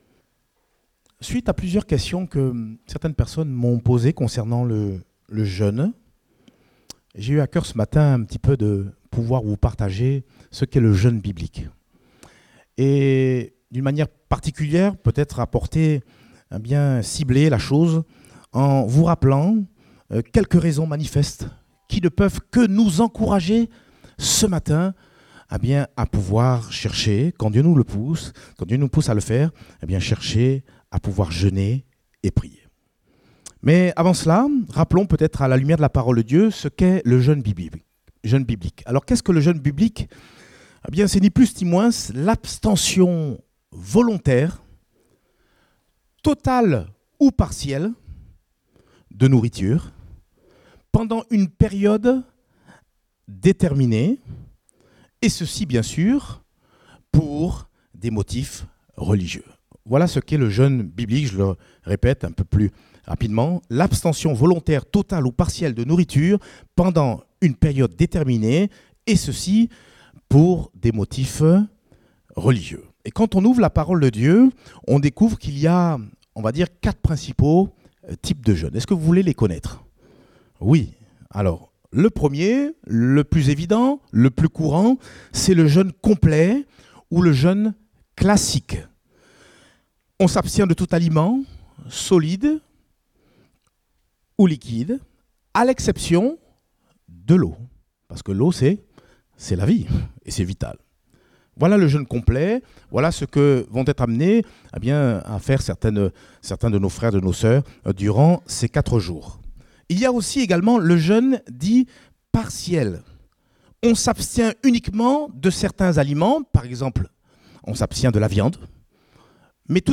Date : 23 septembre 2018 (Culte Dominical)